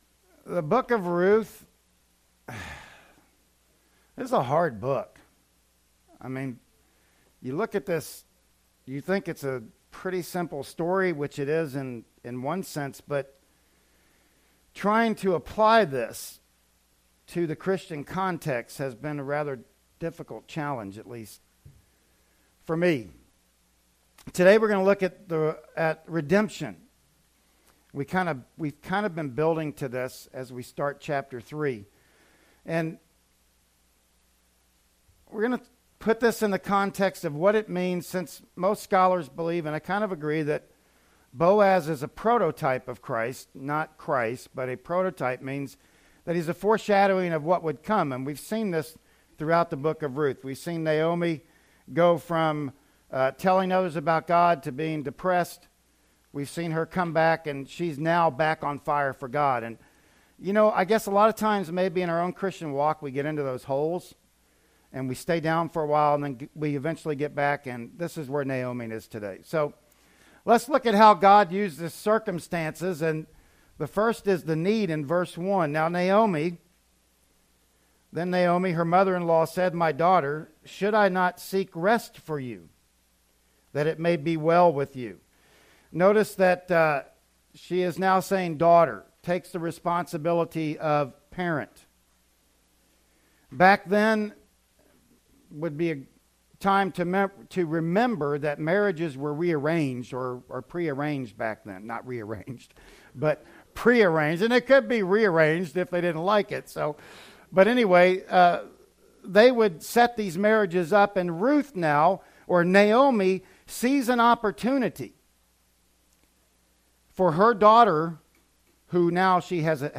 "Ruth 3:1-9" Service Type: Sunday Morning Worship Service Bible Text